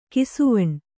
♪ kisuvaṇ